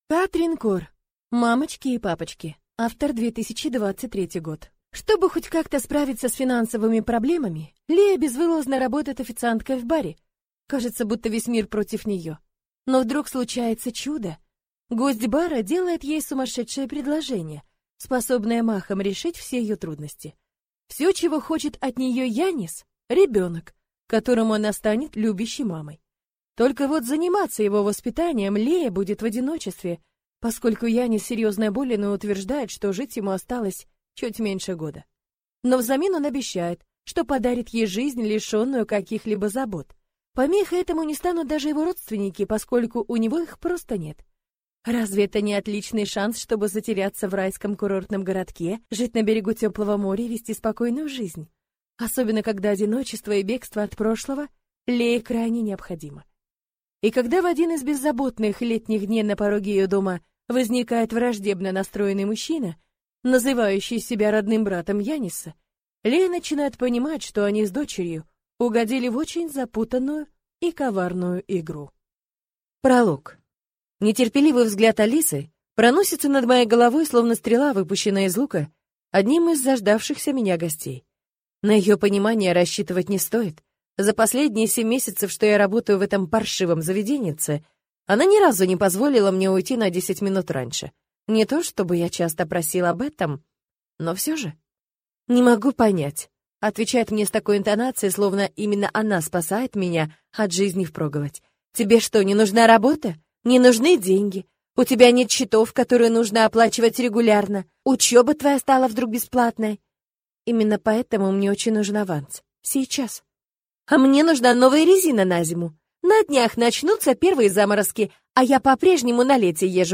Аудиокнига Мамочки и папочки | Библиотека аудиокниг
Прослушать и бесплатно скачать фрагмент аудиокниги